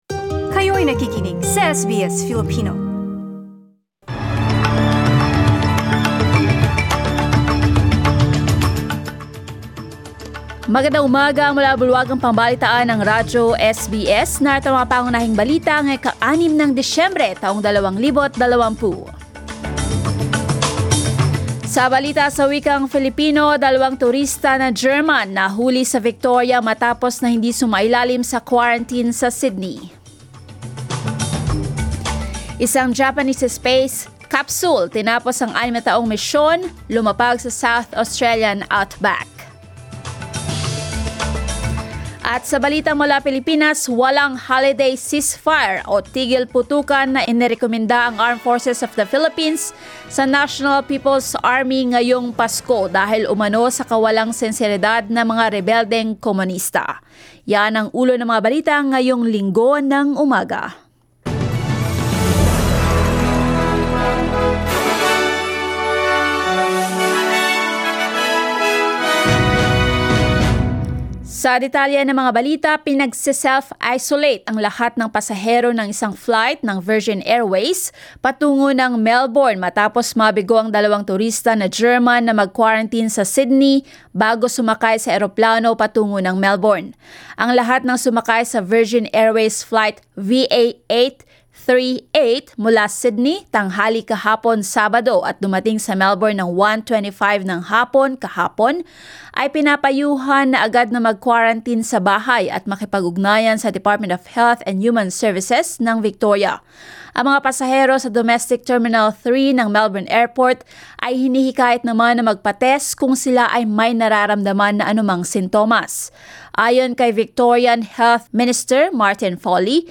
SBS News in Filipino, Sunday 06 December